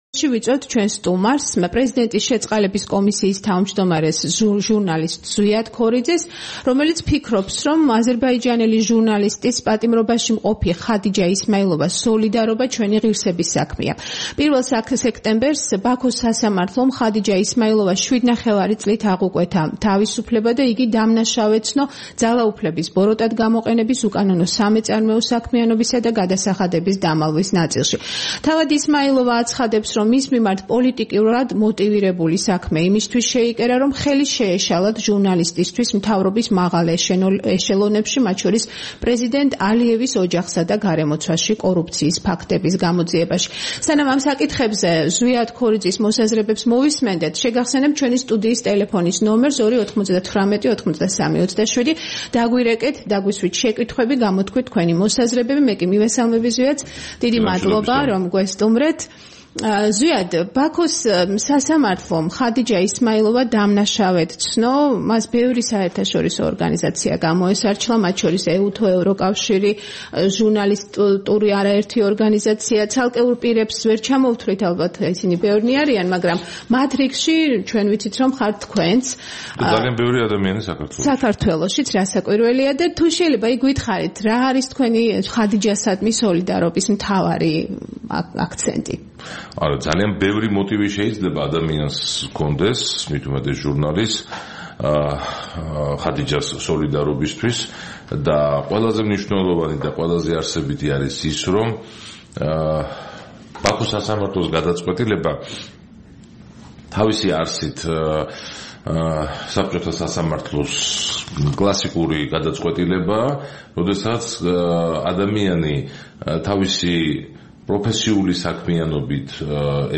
3 სექტემბერს „დილის პროგრამის“ სტუმარი იყო პრეზიდენტის შეწყალების კომისიის თავმჯდომარე, ჟურნალისტი ზვიად ქორიძე. მან ილაპარაკა ხადიჯა ისმაილოვას საქმეზე.
საუბარი ზვიად ქორიძესთან